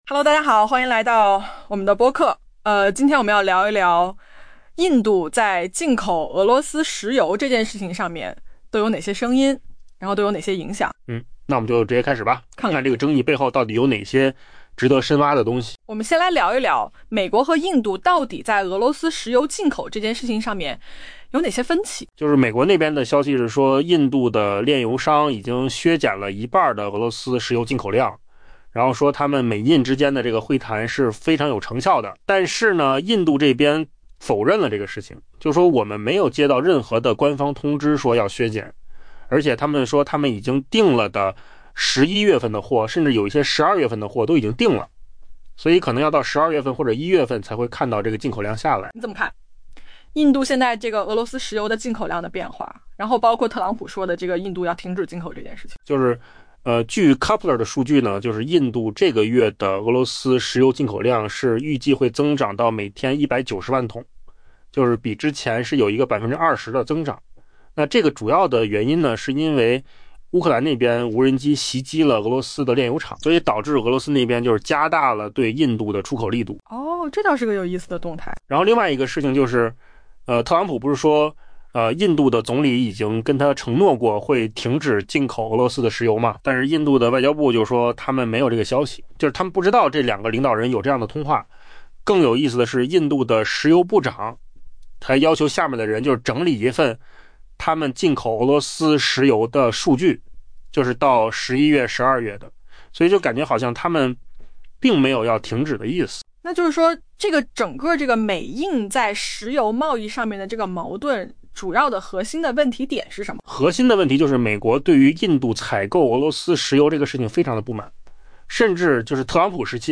AI 播客：换个方式听新闻 下载 mp3 音频由扣子空间生成 美国白宫一名官员表示，印度已将其购买的俄罗斯石油量减半， 但印度消息人士称，目前尚未看到立即削减的情况。